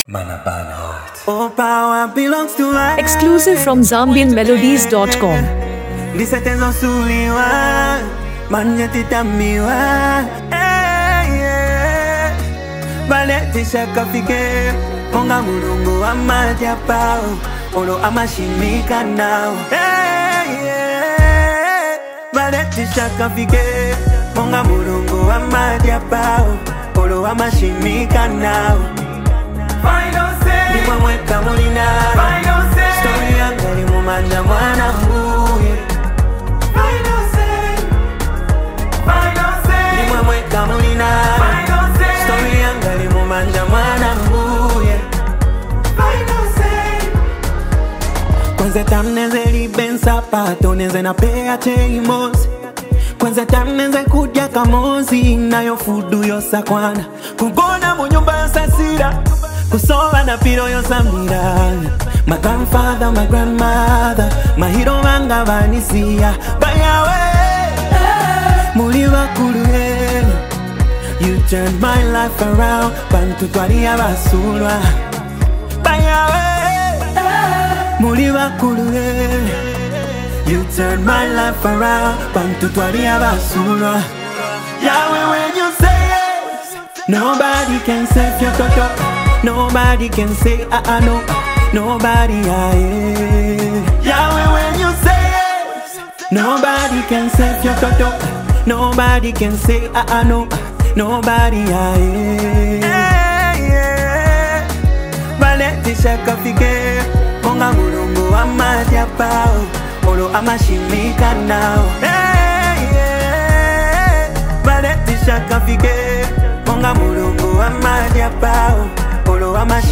soulful melodies
emotionally charged vocals